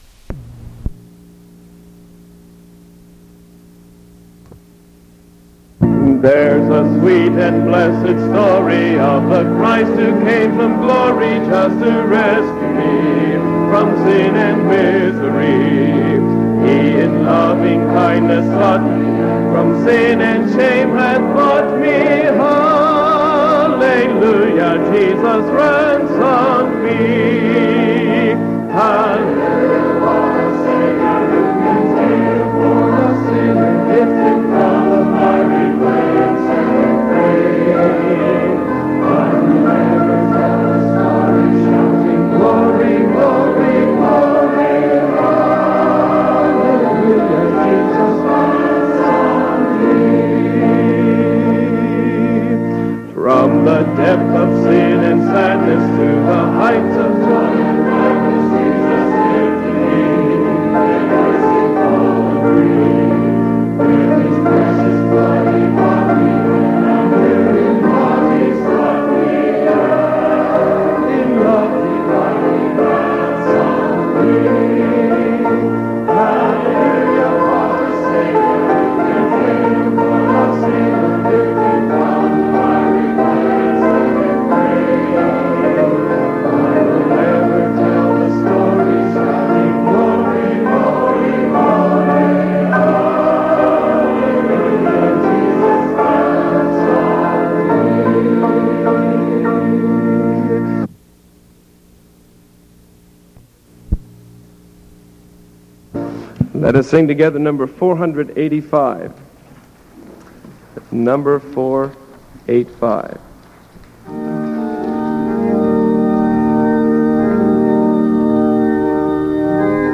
Sermon November 11th 1973 PM | WHDL